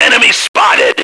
Index of /action/sound/radio/male